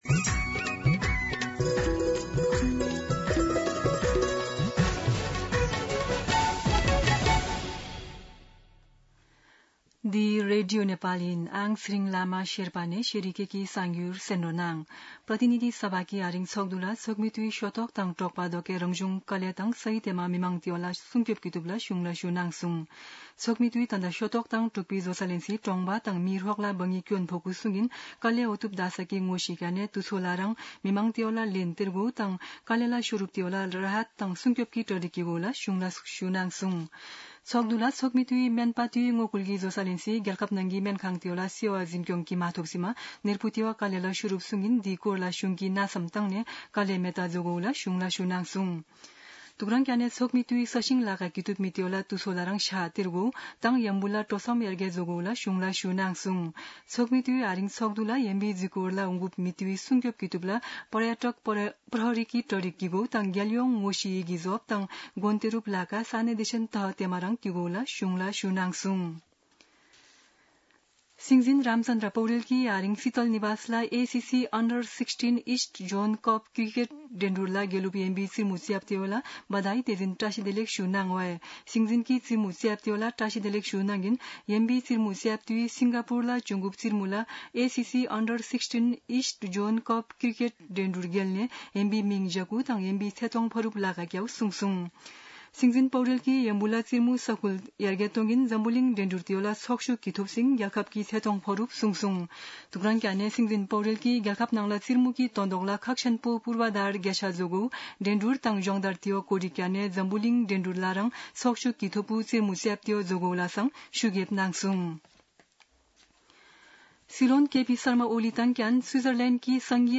शेर्पा भाषाको समाचार : २३ असार , २०८२